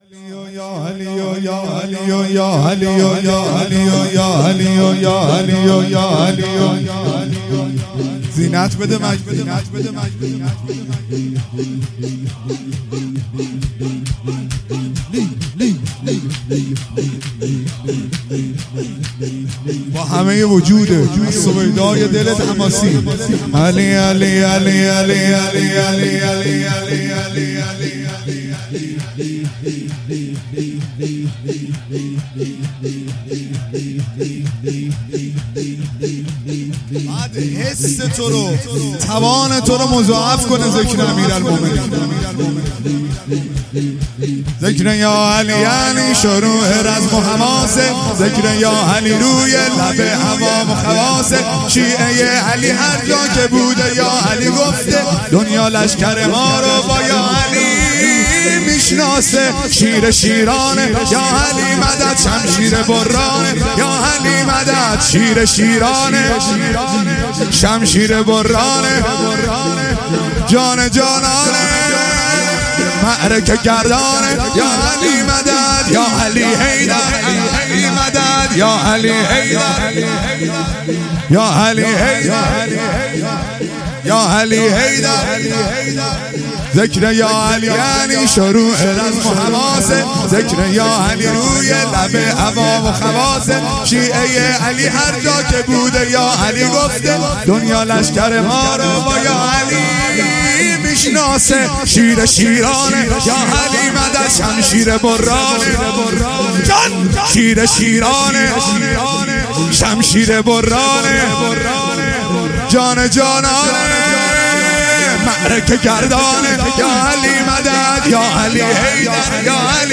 شور | ذکر با علی یعنی